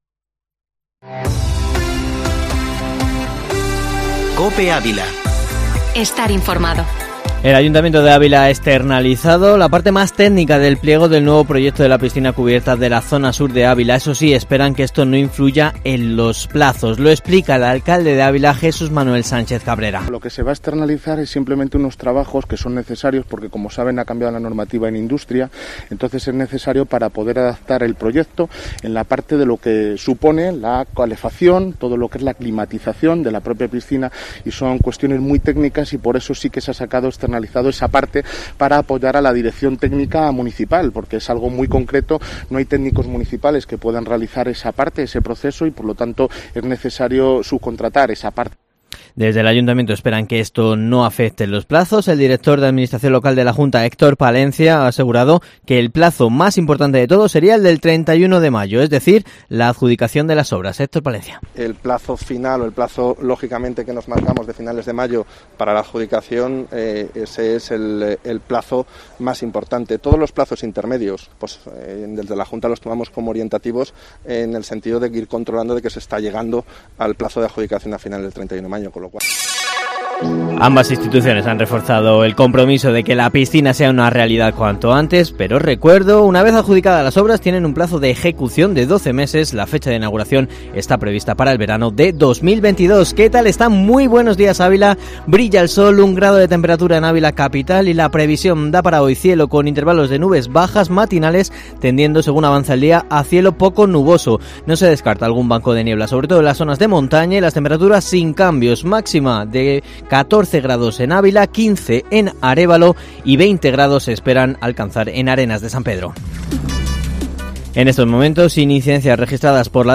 Informativo matinal Herrera en COPE Ávila 17/03/2021